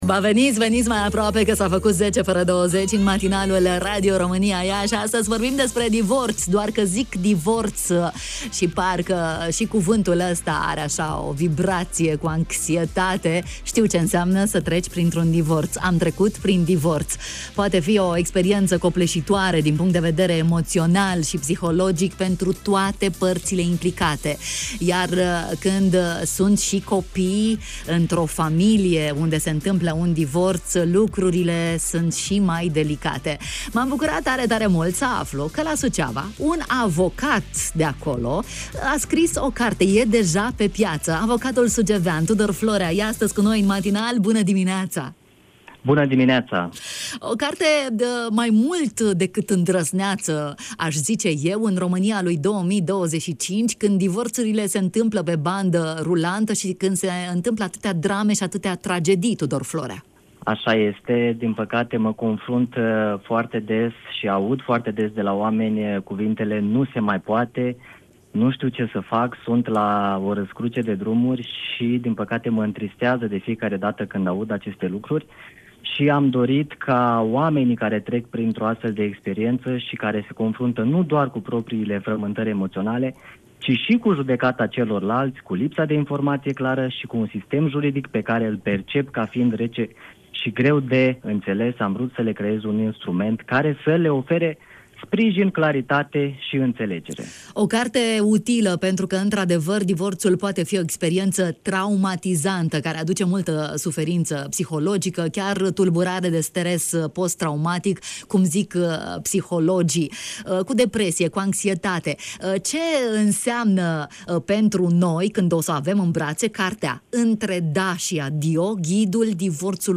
în direct la Radio România Iași: